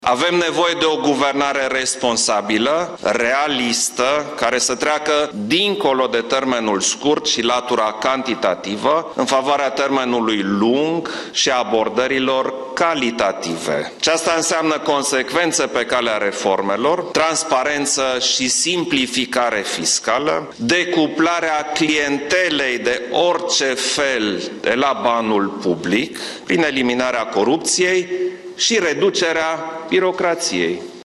Avem nevoie de o guvernare responsabilă și de eliminarea corupției – iată mesajul președintelui Klaus Iohannis, prezent la lansarea strategiei Guvernului “România competitivă”.